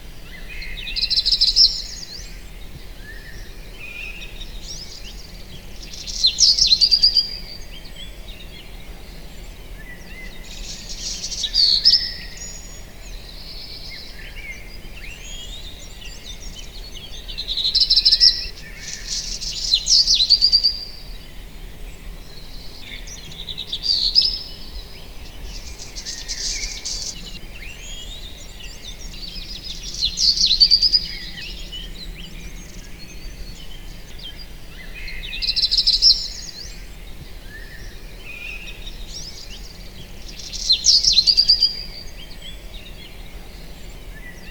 دانلود آهنگ بهار از افکت صوتی طبیعت و محیط
جلوه های صوتی
دانلود صدای بهار از ساعد نیوز با لینک مستقیم و کیفیت بالا